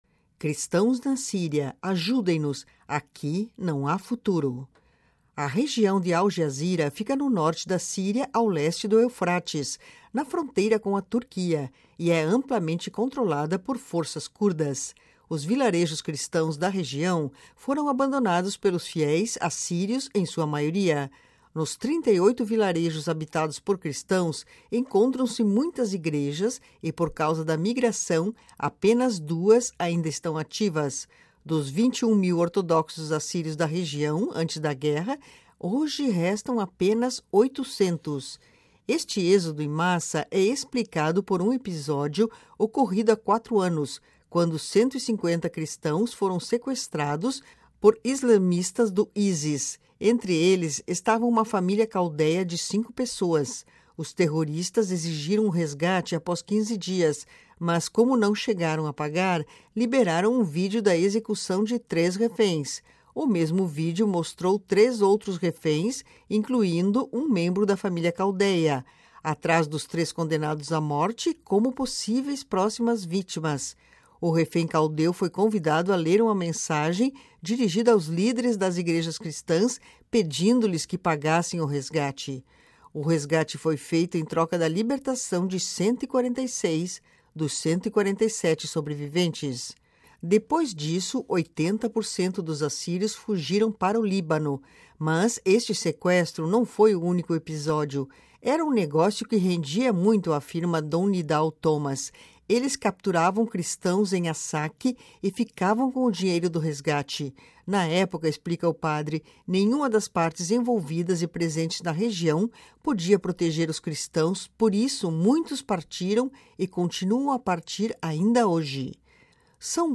Em uma entrevista ao Vatican News, descreve a dramática situação dos cristãos na região e a grande fuga em busca de um futuro melhor